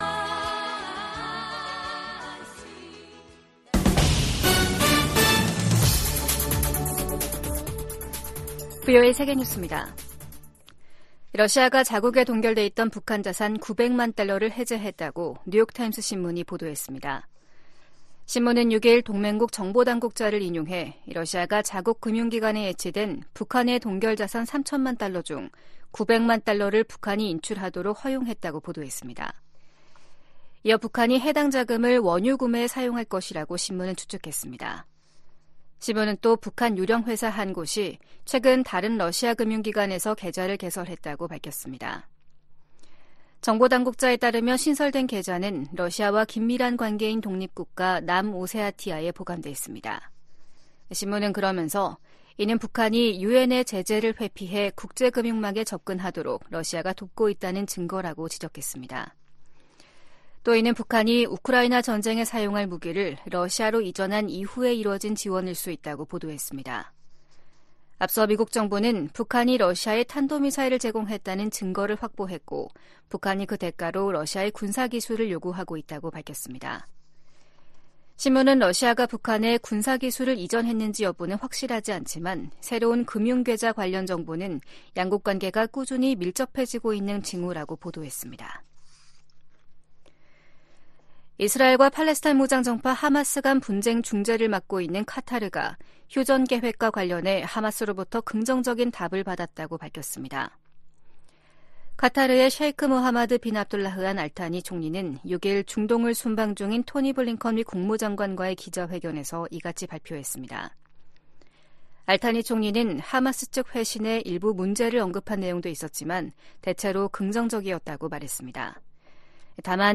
VOA 한국어 아침 뉴스 프로그램 '워싱턴 뉴스 광장' 2024년 2월 7일 방송입니다. 북한 핵 정책을 비판한 윤석열 한국 대통령에 대해 러시아 외무부 대변인이 비판 논평을 내면서 갈등이 악화될 조짐을 보이고 있습니다. 미국 정부는 북한의 미사일 경보 정보를 한국· 일본과 계속 공유할 것이라고 밝혔습니다. 오는 11월 도널드 트럼프 전 대통령이 당선되면 임기 초 북한과 협상할 가능성이 있다고 존 볼튼 전 국가안보보좌관이 VOA 인터뷰에서 전망했습니다.